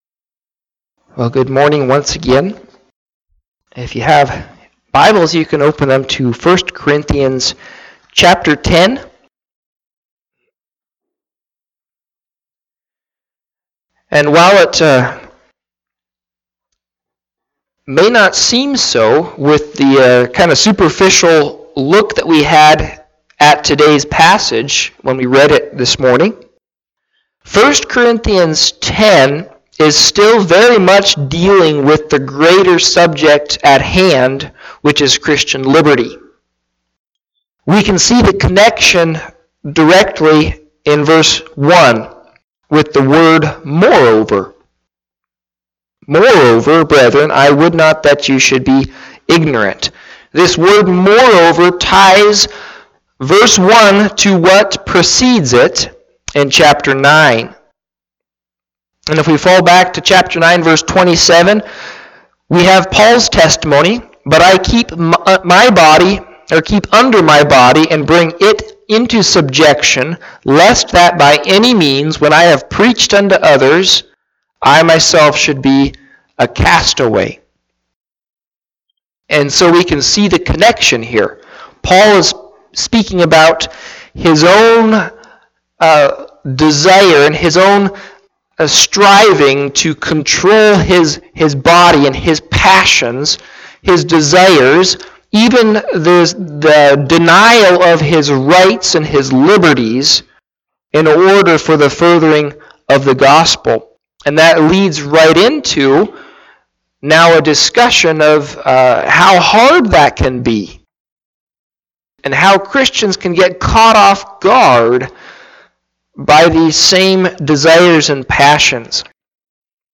Listen to Audio of the sermon or Click Facebook live link above.
Service Type: Morning Sevice